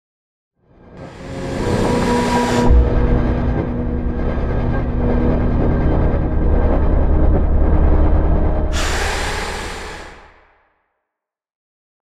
conjuration-magic-sign-circle-complete.ogg